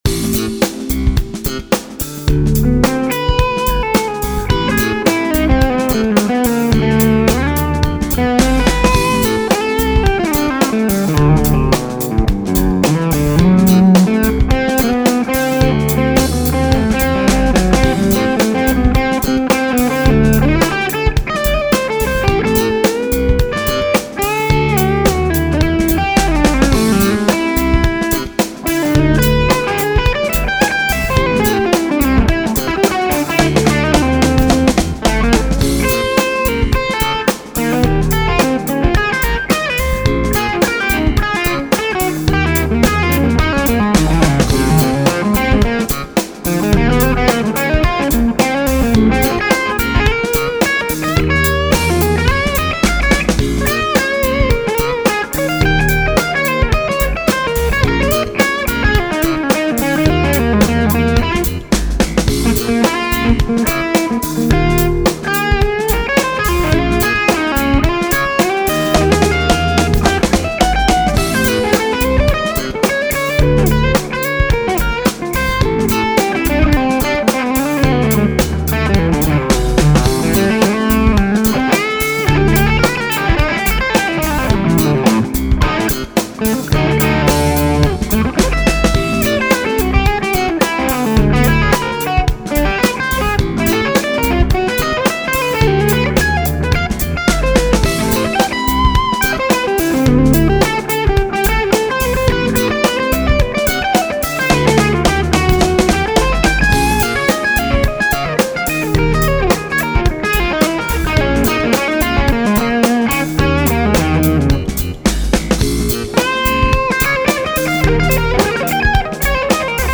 Centrifugal Jam, dirty tone then clean tone
First half with OD, then clean tone....
OD is bridge pup, clean is neck. Punched in the clean solo on a lick I did not like on the OD solo.
Amp is 6L6 50 watt with Bluesmaster PI, presence, and feedback. HRM stack for OD tone. G1265 speaker.
I think maybe the treble was a hair high on this one.
Fwiw, the SM57 mic is a little spikey me thinks.....
Great clip ! Nice and smooth 8)
A bit bright but terrific tone, especially the lower notes.
Terrific clean tones too.